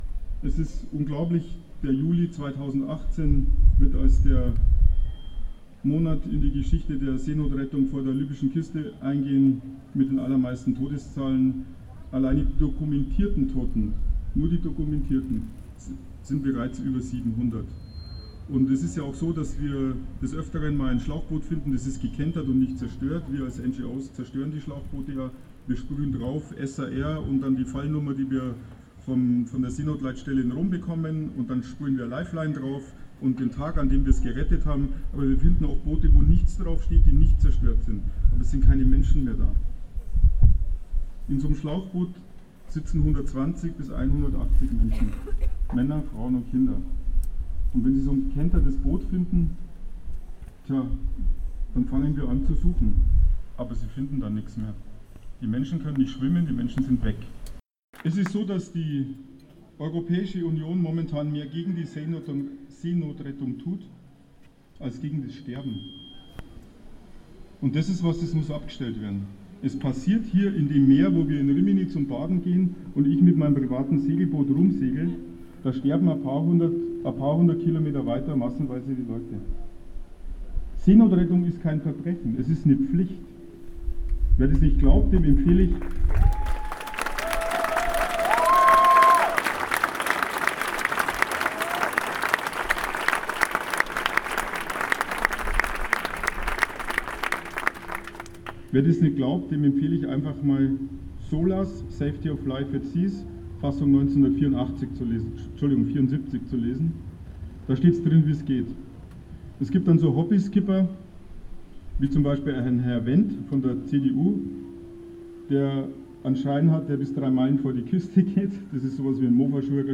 Seebrücke-Demo Freiburg
Am Samstag mittag fanden sich am Platz der alten Synagoge rund 200 bis 250 Menschen ein, um gegen die zwangsweise Aussetzung der zivilen Seenotrettung im Mittelmeer zu protestieren.